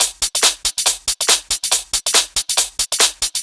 VTDS2 Song Kit 07 Female Dr Love Loop.wav